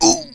PlayerHurt3.wav